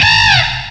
sovereignx/sound/direct_sound_samples/cries/archen.aif at master